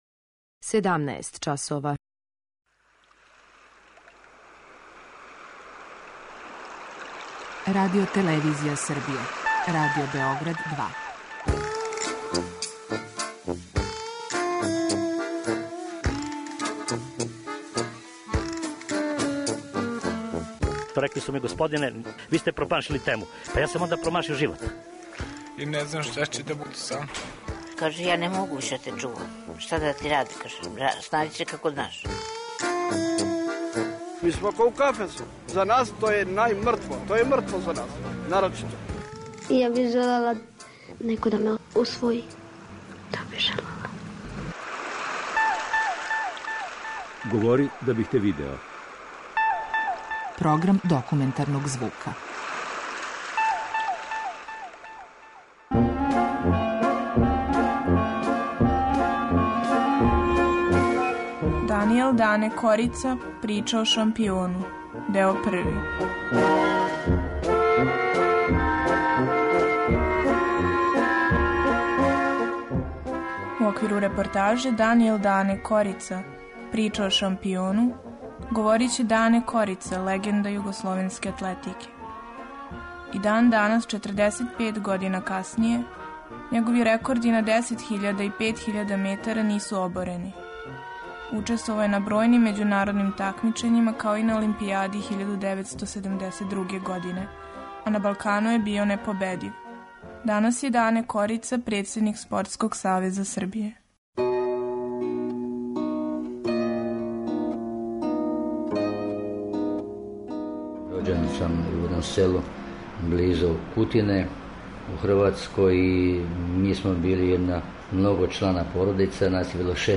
Документарни програм: Данијел Дане Корица - прича о шампиону, део први
Говори Дане Корица, легенда југословенске атлетике.